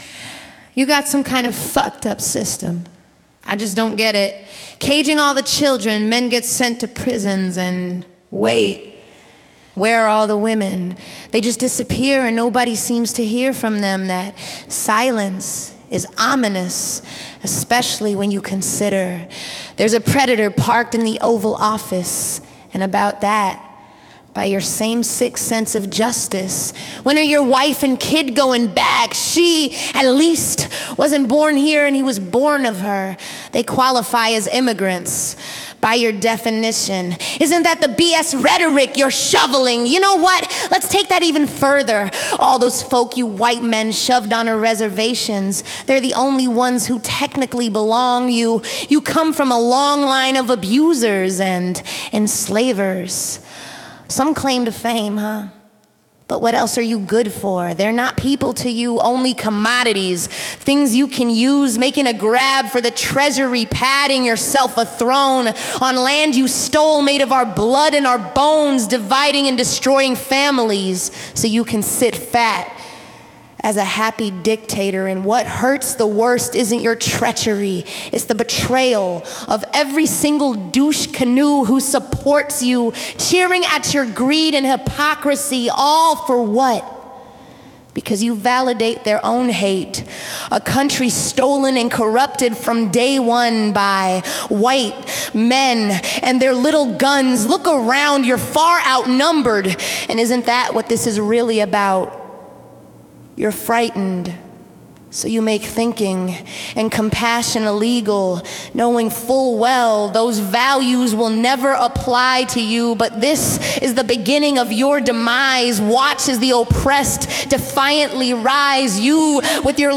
u1034-We-the-People-spoken.mp3